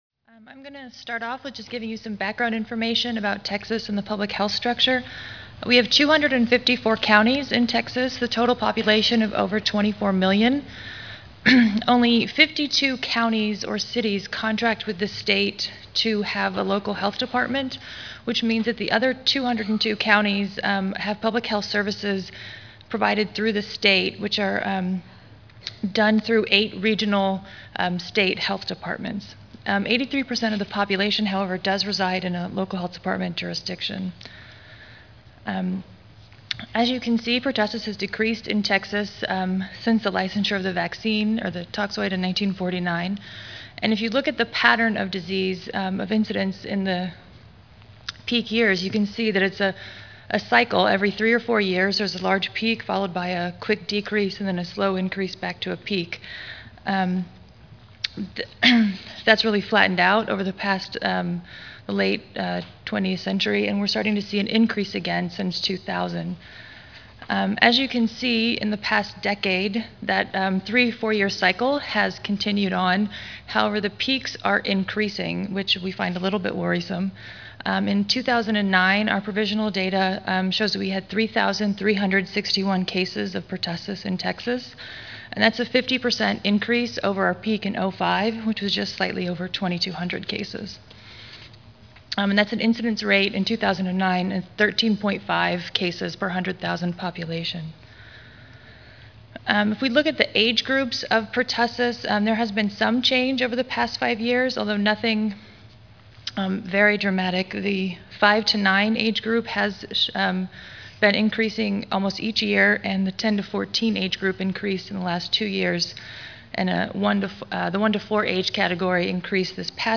MPH Audio File Slides Recorded presentation